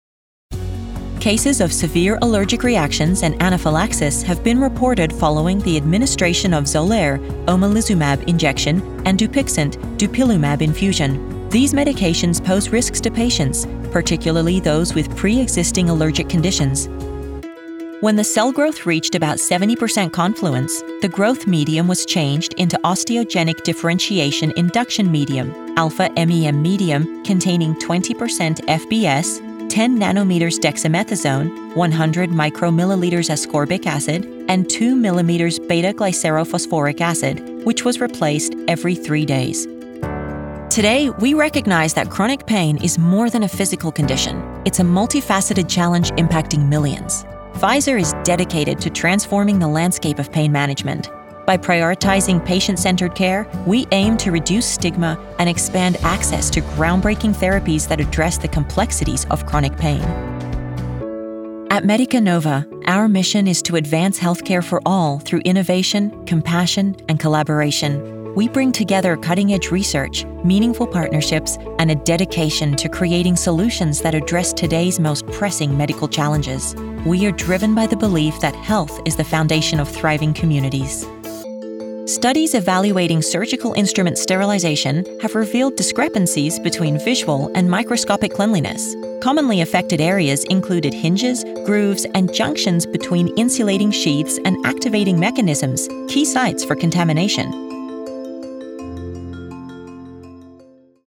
Female
I'm a trained actor with a grounded, connected sound.
Medical Narrations
Words that describe my voice are Relatable, Conversational, Versatile.